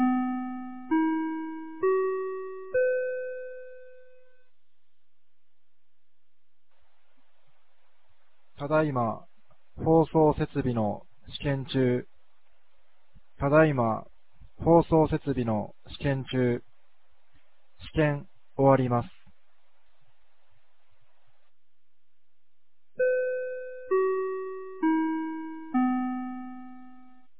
2025年11月29日 16時03分に、由良町から全地区へ放送がありました。
放送音声